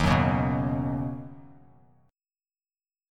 D#m7#5 chord